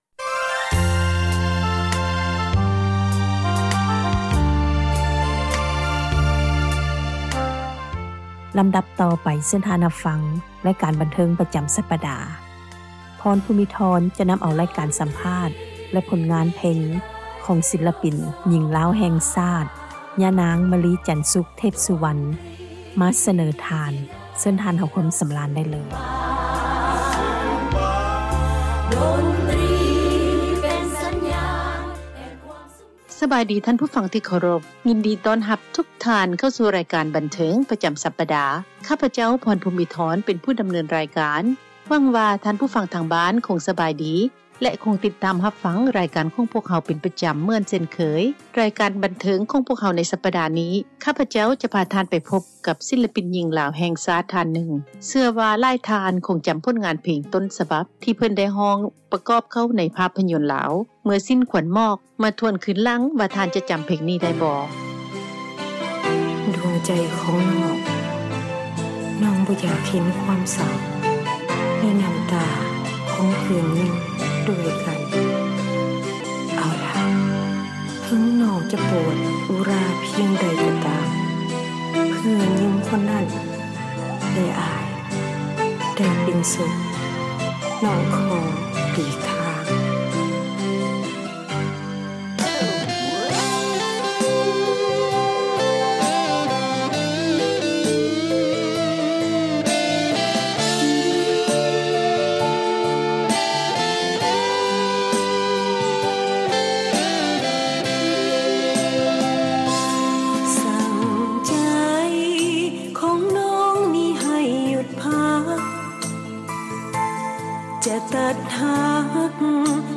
ສັມພາດ
ການສັມພາດ ແລະ ຜົລງານເພງ